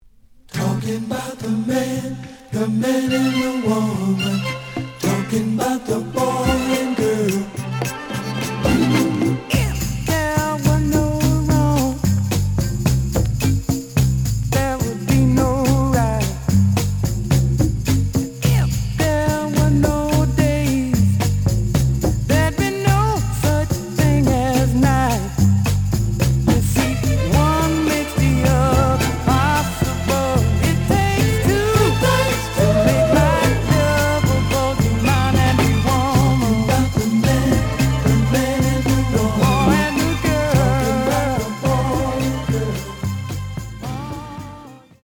試聴は実際のレコードから録音しています。
●Genre: Soul, 70's Soul
●Record Grading: VG+~EX- (両面のラベルにダメージ。盤に歪み。多少の傷はあるが、おおむね良好。)